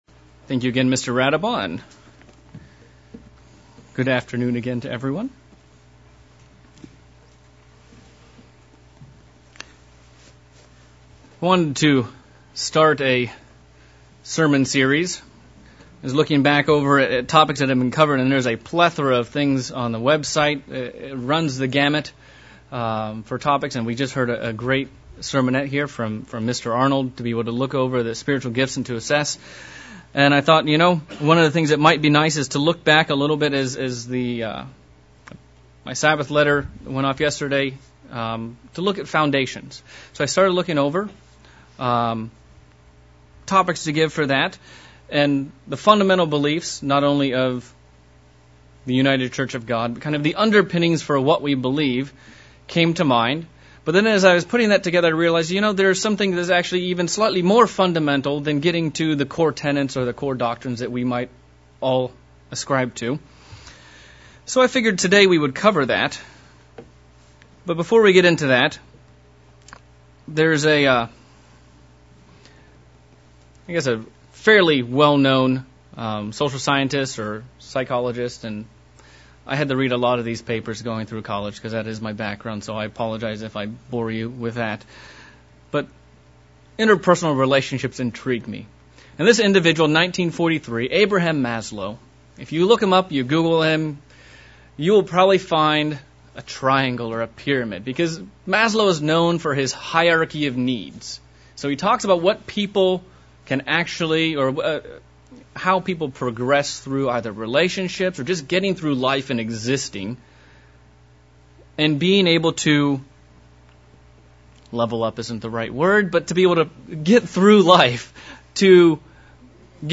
Fundamentals of Belief sermon looking at the existence of God and some of the proofs of his existence